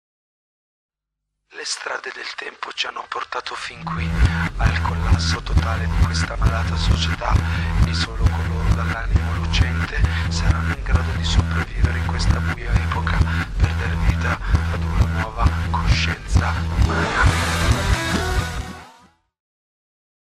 pop rock
Tipo di backmasking Rovesciato